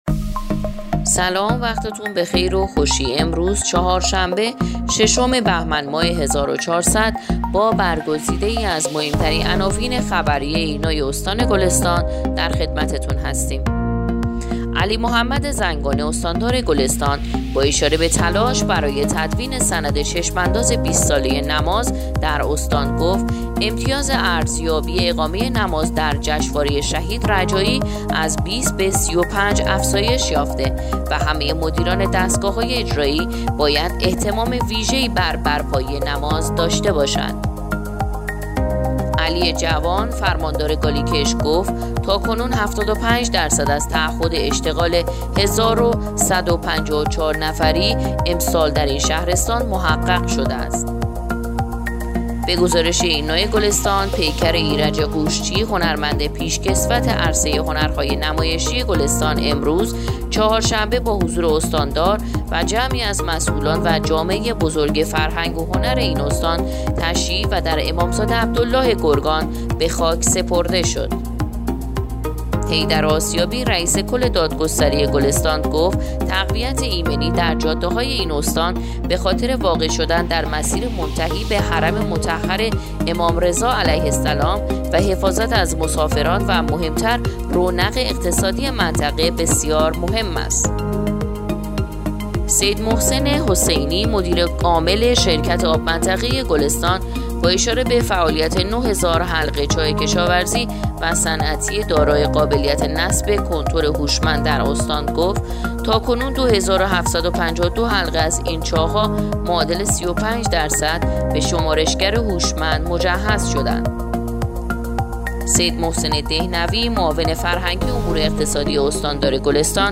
پادکست/ اخبار شبانگاهی ششم بهمن ماه ایرنا گلستان